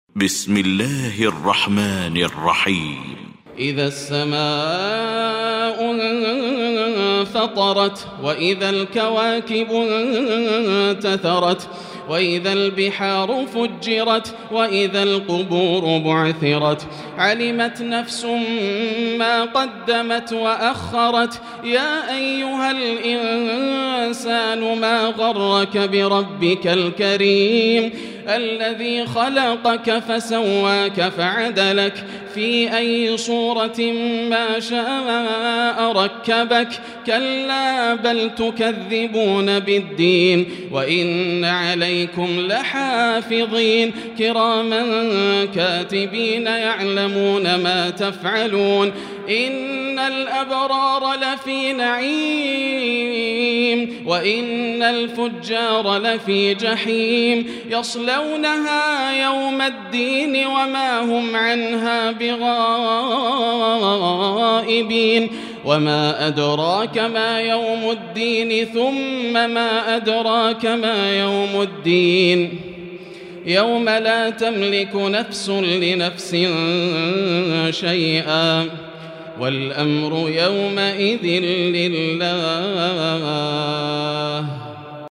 المكان: المسجد الحرام الشيخ: فضيلة الشيخ ياسر الدوسري فضيلة الشيخ ياسر الدوسري الانفطار